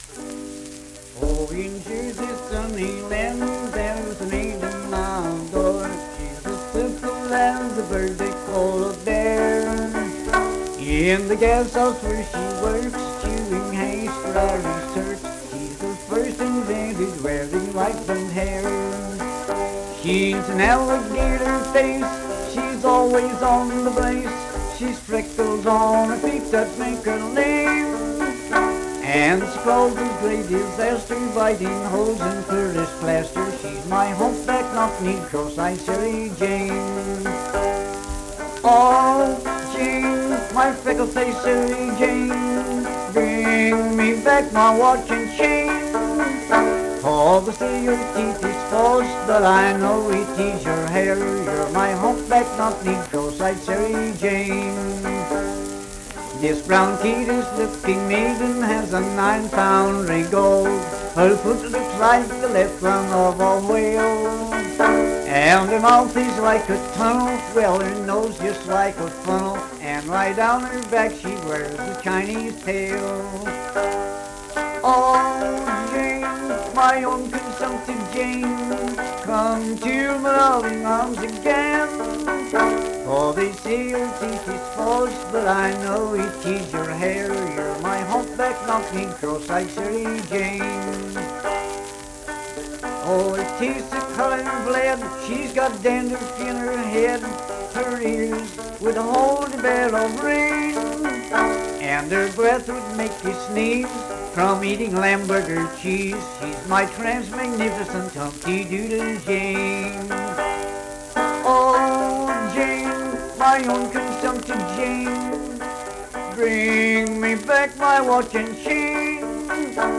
Banjo accompanied vocal music performance
Verse-refrain 3(12w/R).
Voice (sung), Banjo